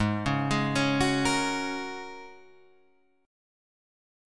G#7sus2sus4 Chord